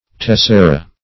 Tessera \Tes"se*ra\, n.; pl.